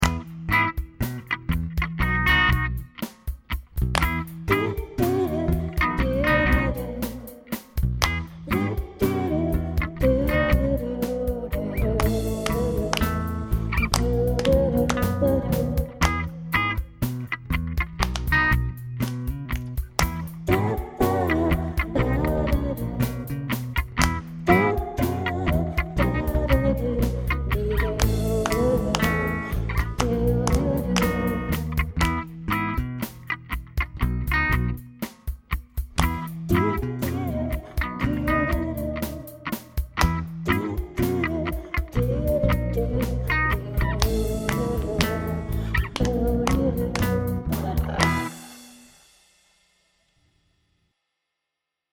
Punk.